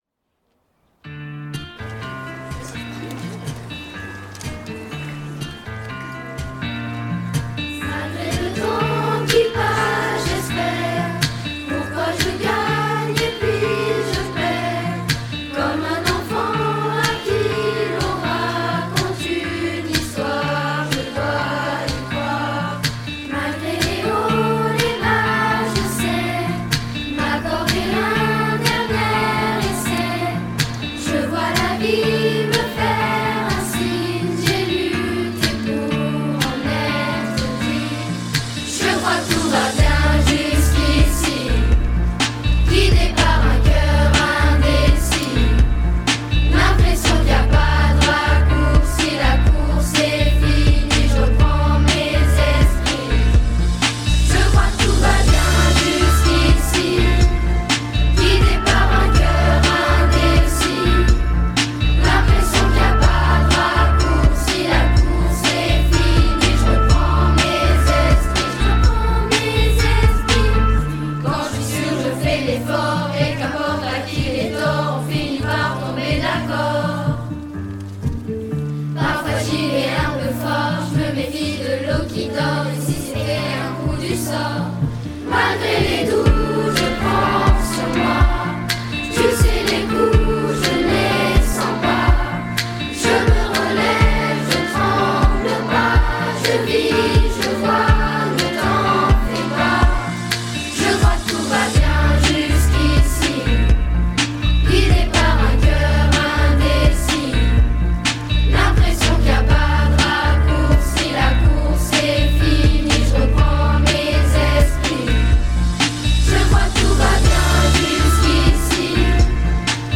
Et pour prolonger le plaisir de chante ensemble, lors de la fête de la musique 2022, un concert privé en salle de musique a électrisé le public présent.